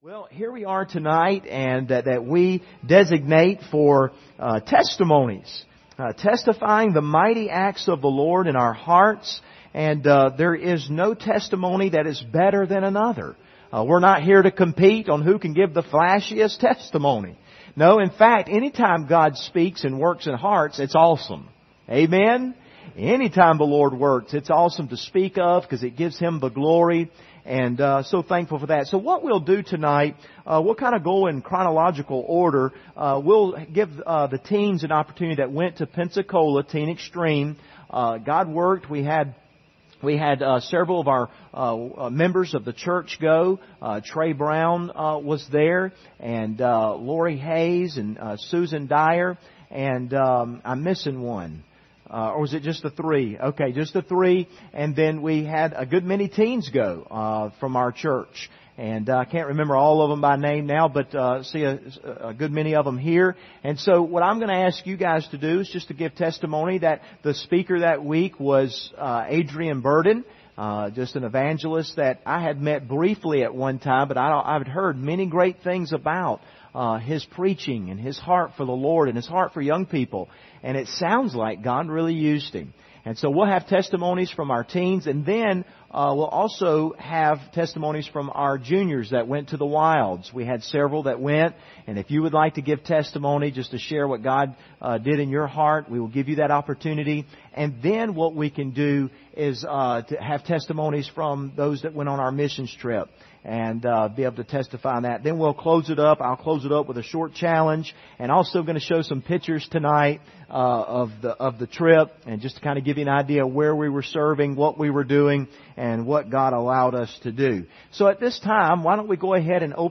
Preacher: CCBC Members | Series: Testimonies